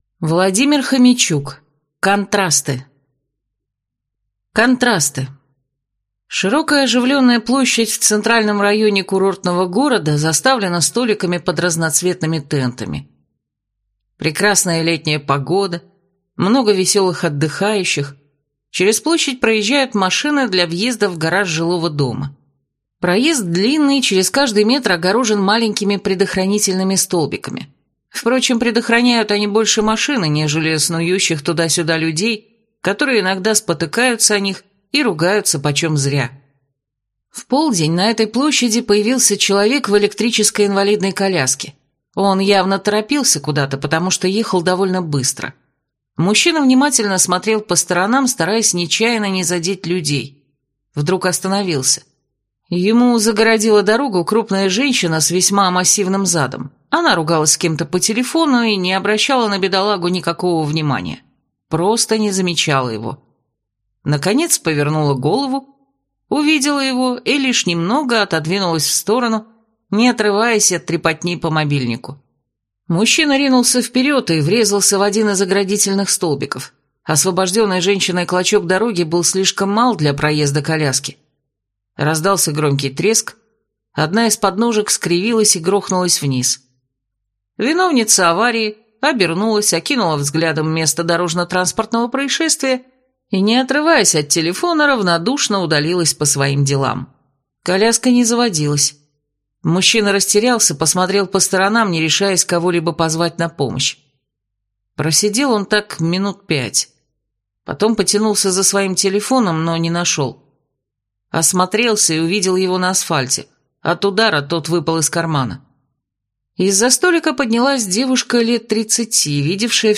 Аудиокнига Контрасты | Библиотека аудиокниг